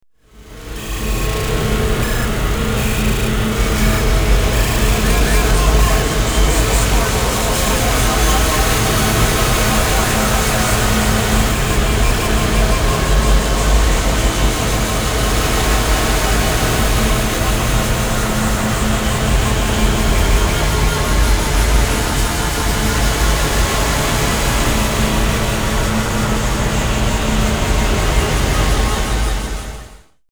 For noise sickos only!
3 song remix CD is pure digital regurgitation